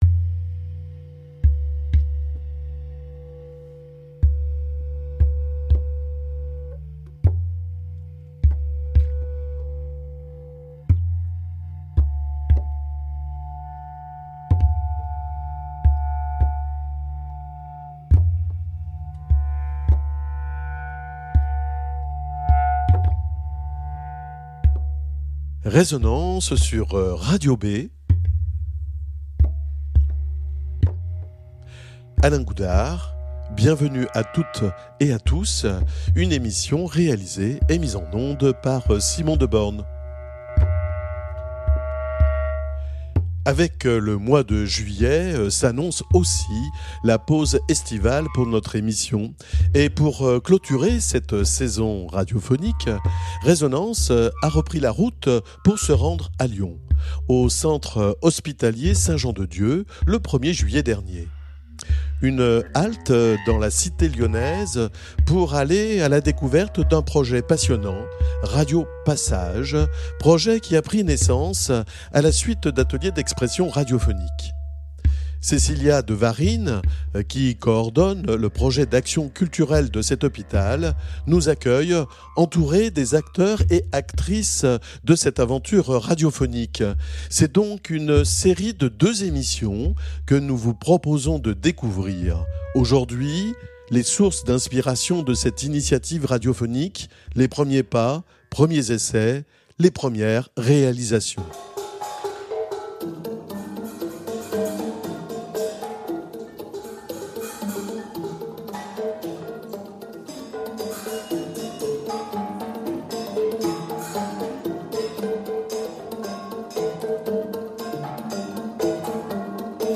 Leture des textes